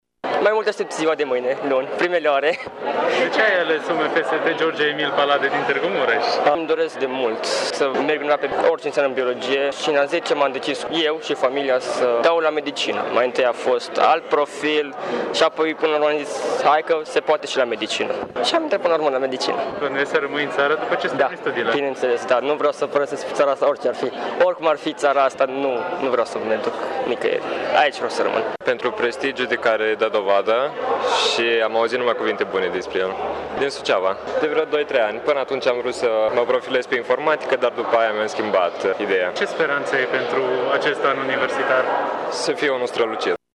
Tinerii aflați la început de drum consideră că această universitate este una prestigioasă, cu un bun renume :